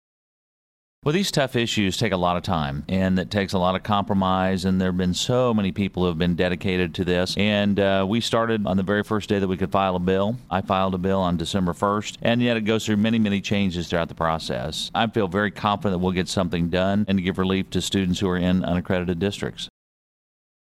The following audio comes from the above interview with Sen. Pearce, for the week of April 27, 2015.